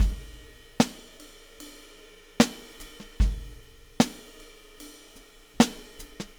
Weathered Beat 03.wav